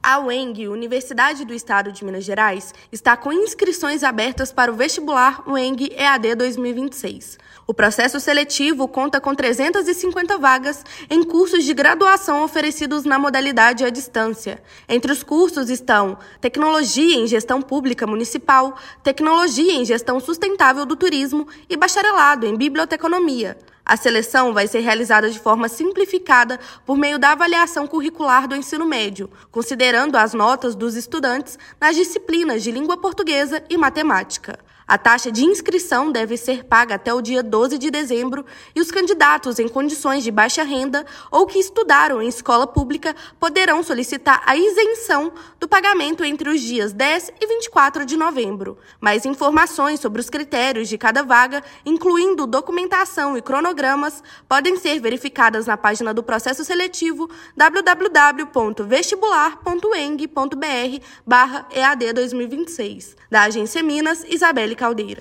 [RÁDIO] Uemg continua com inscrições abertas para Vestibular EaD 2026
São mais de 350 vagas para cursos nas áreas de turismo, gestão pública e biblioteconomia. Ouça matéria de rádio.